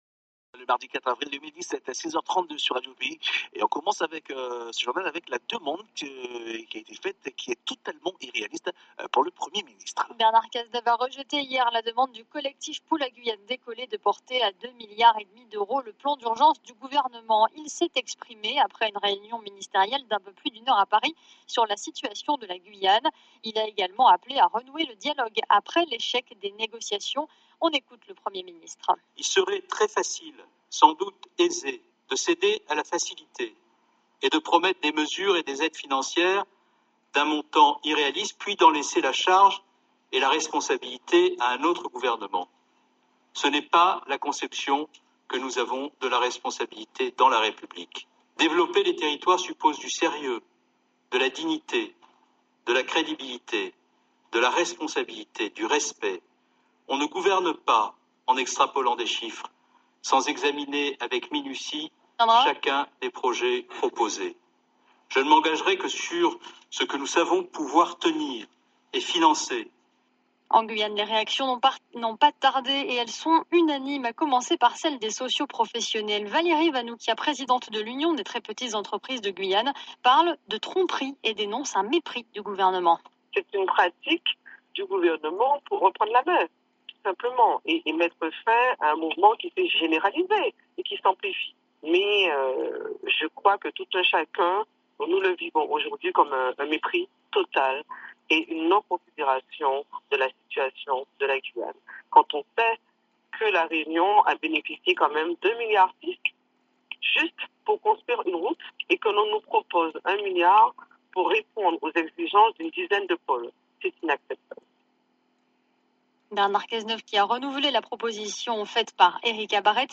GUYANE CRISE .Le dernier flash de Radio Péyi sur la situation en Guyane